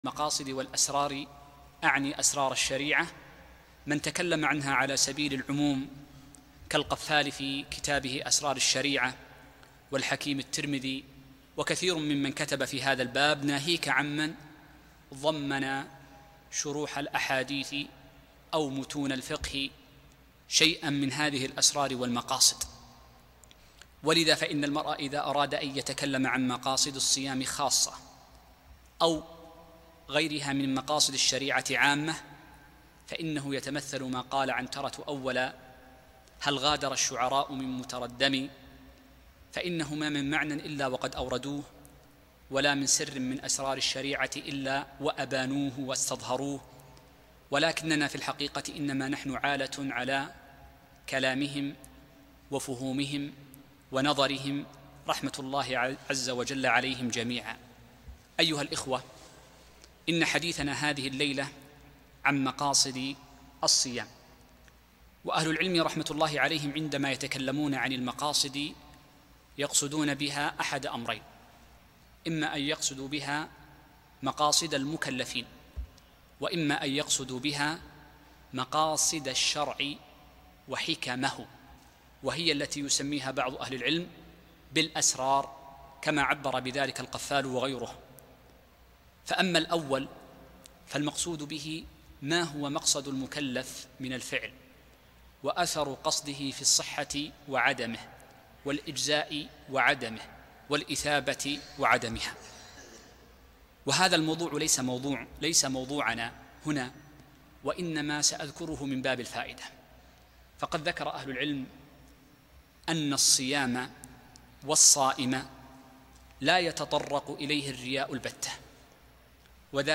مقاصد الصيام محاضرة في جامع الراجحي